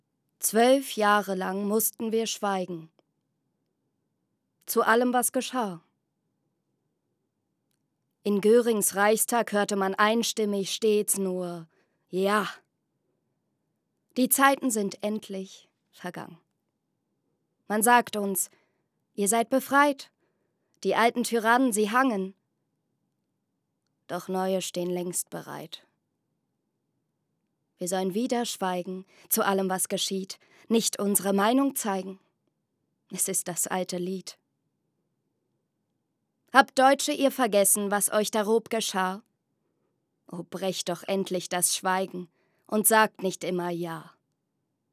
Zwei Gedichte